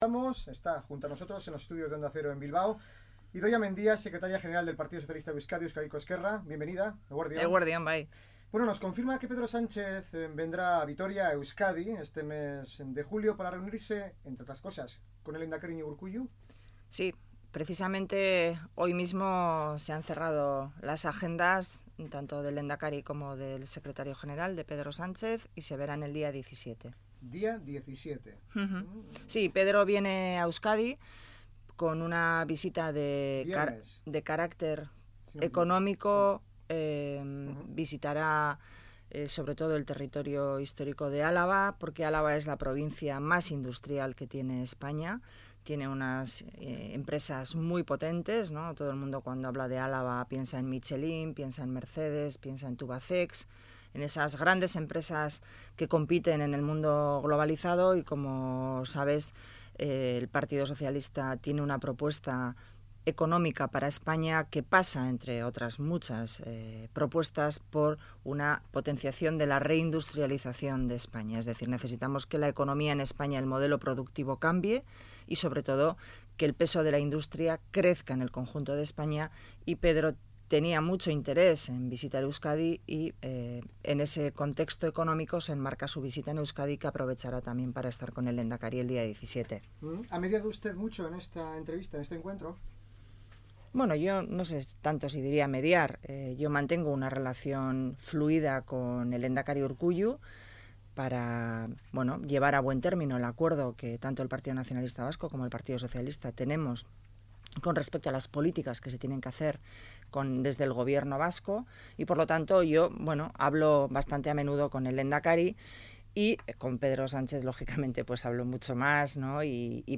La Secretaria General del PSE-EE, que ha sido entrevistada en Onda Cero, ha destacado el "interés" que Pedro Sánchez tiene además por aprovechar su visita Euskadi para mantener un encuentro con el lehendakari Urkullu, ya que desde que fue elegido Secretario General del PSOE, su "actividad enorme" ha impedido celebrarlo.